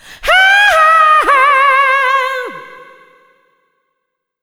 SCREAM13  -L.wav